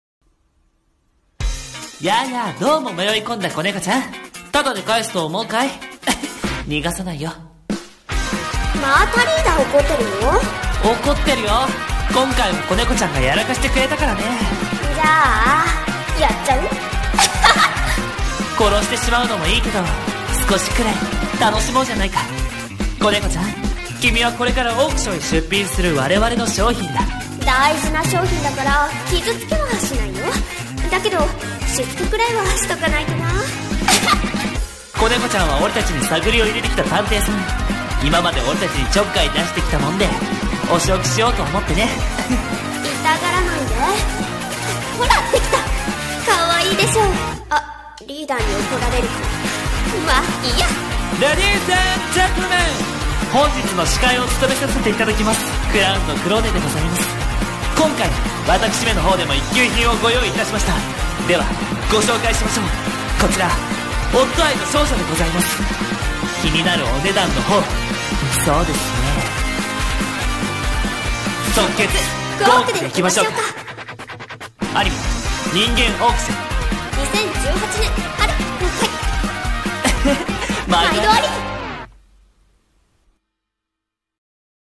CM風声劇】人間オークション